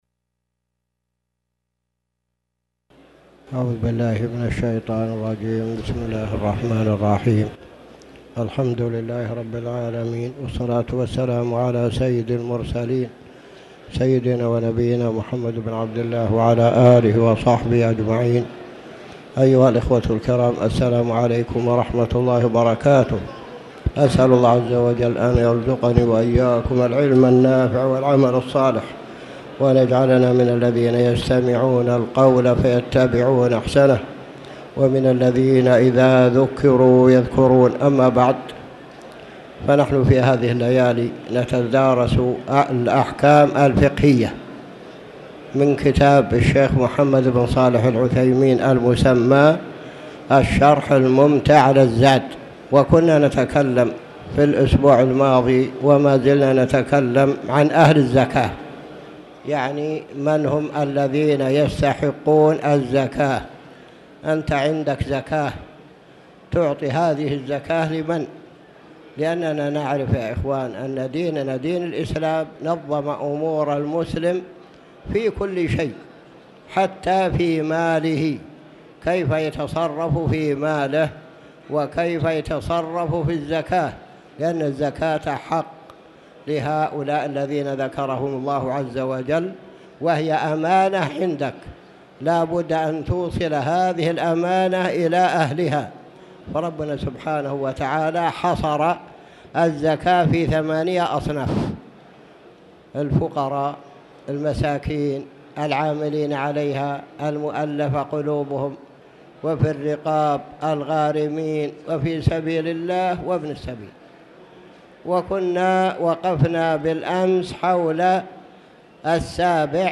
تاريخ النشر ٥ جمادى الأولى ١٤٣٩ هـ المكان: المسجد الحرام الشيخ